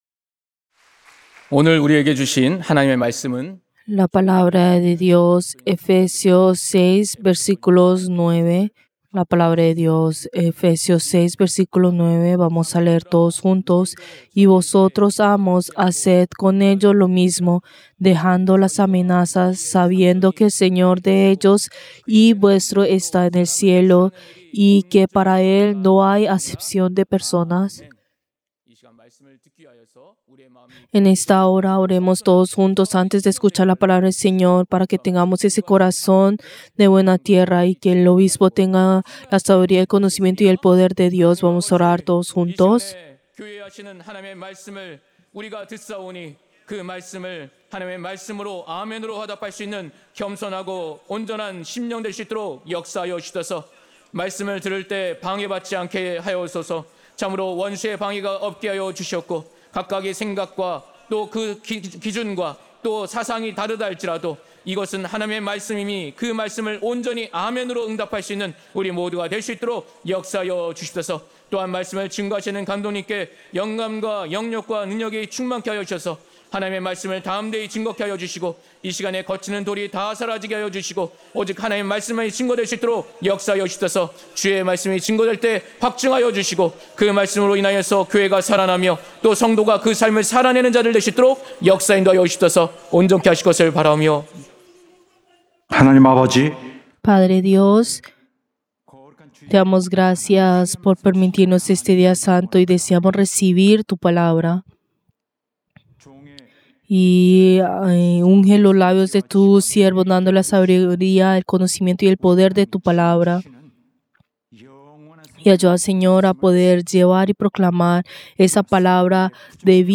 Servicio del Día del Señor del 1 de junio del 2025